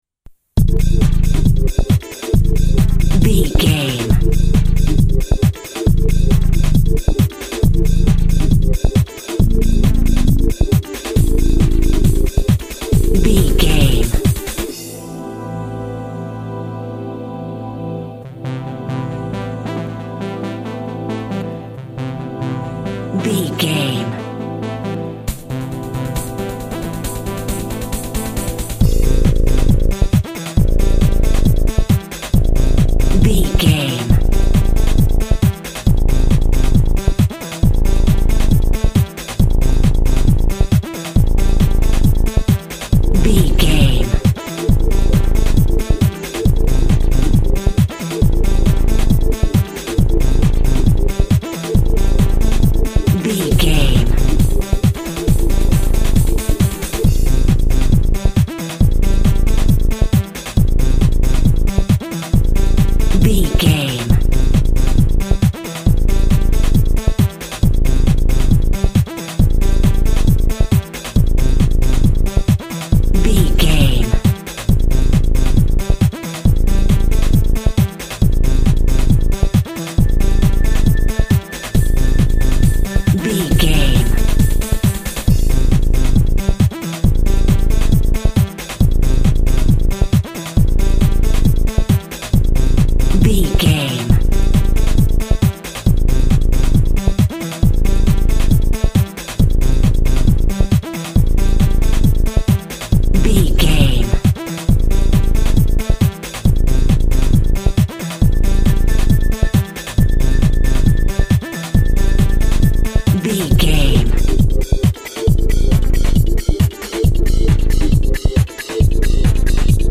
Aeolian/Minor
funky
groovy
uplifting
driving
energetic
electronic
synth lead
synth bass
electronic drums
Synth Pads